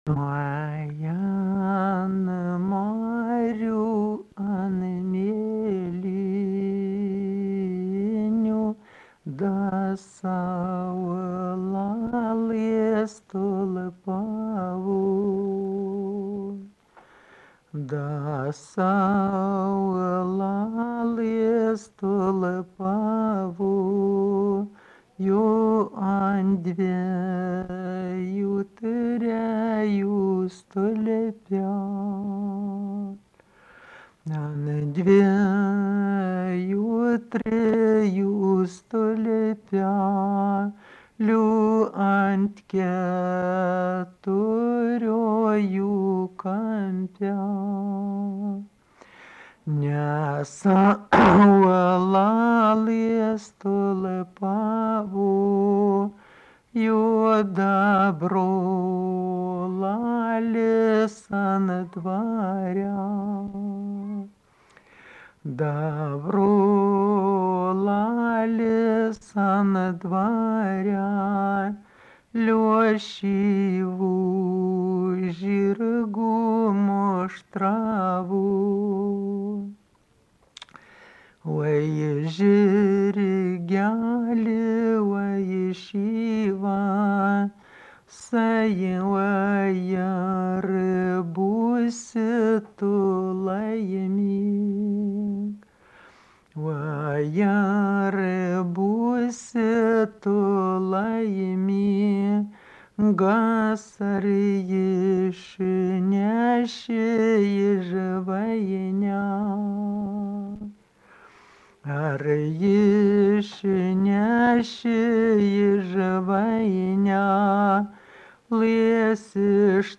daina, vaikų
Kalvių k. Mečiūnai
Atlikimo pubūdis vokalinis